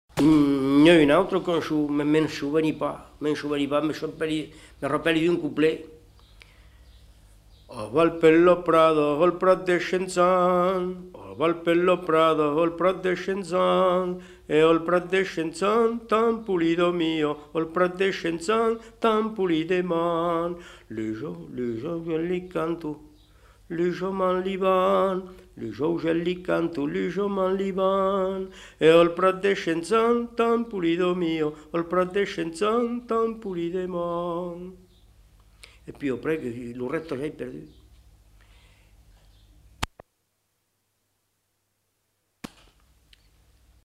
Aire culturelle : Périgord
Lieu : Daglan
Genre : chant
Effectif : 1
Type de voix : voix d'homme
Production du son : chanté
Danse : bourrée
Description de l'item : fragment ; 2 c. ; refr.